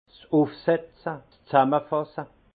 Haut Rhin 's Üfsetze, 's Sàmmafasse
Prononciation 68 Munster